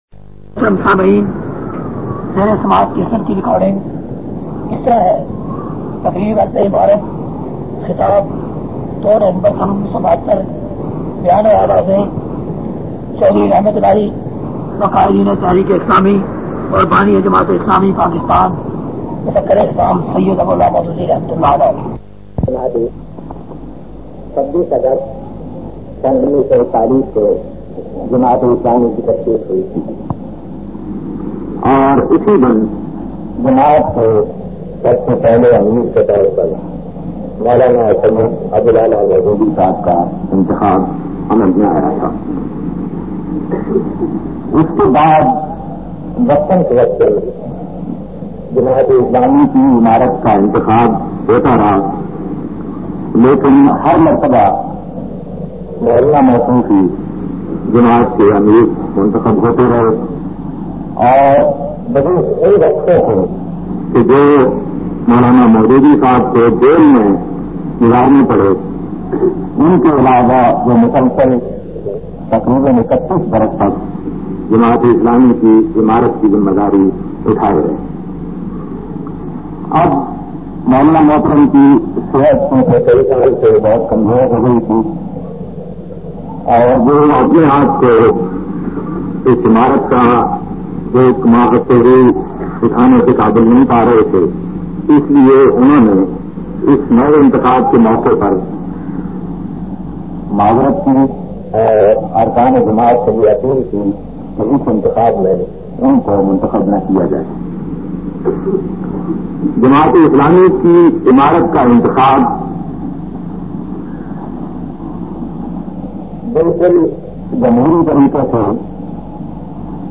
1168_Halaf-Amarat-Ke-Bad-Phela-Khutba_Mian-Tufail.mp3